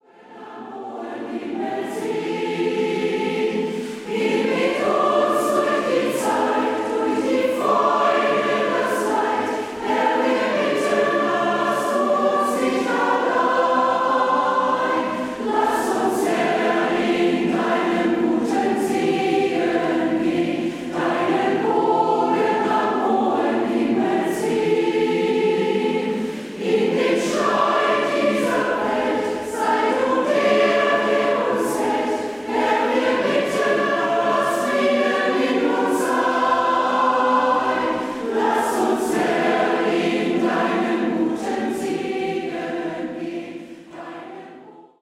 • kurzweilige Zusammenstellung verschiedener Live-Aufnahmen